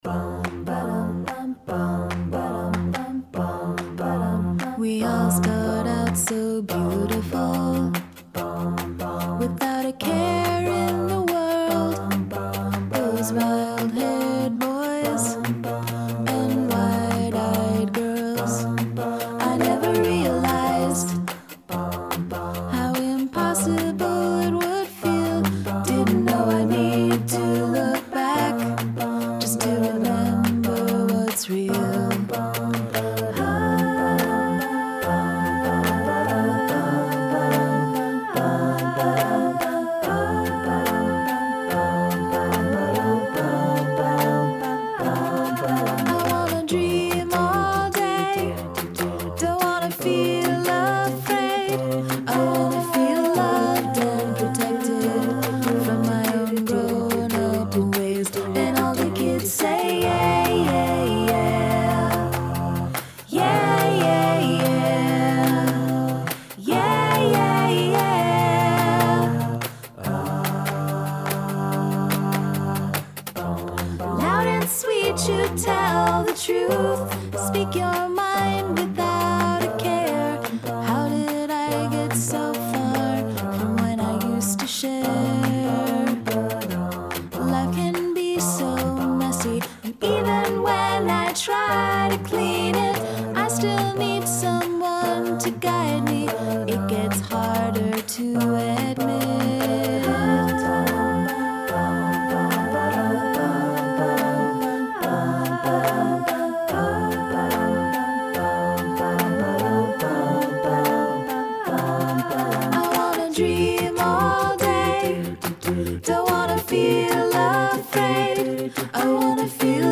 No real instruments.
Sweet and delicate.
They seem to place behind all of the cozy vocal work.